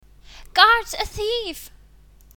Englische Sprecher (f)
Selbsteinsch�tzung / Self-characterisation: innocent / unschuldig